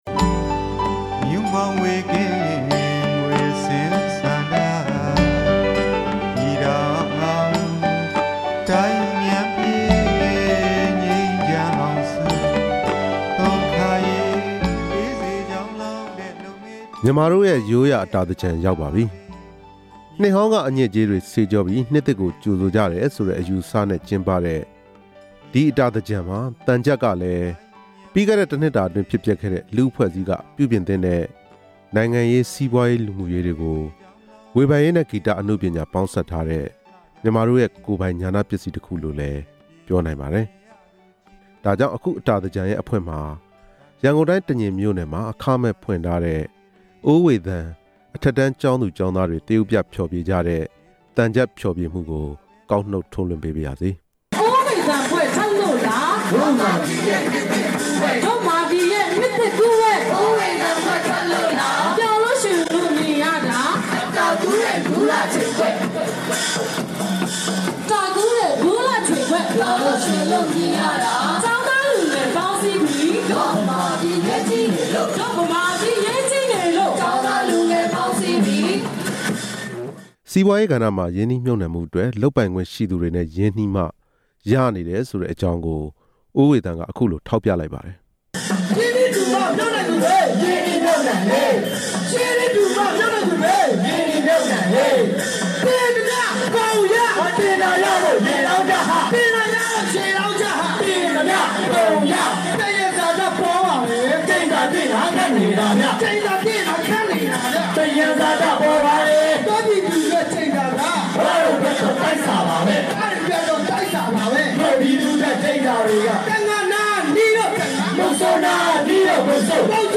သရုပ်ပြဖျော်ဖြေကြတဲ့ သံချပ်ဖျော်ဖြေမှုကို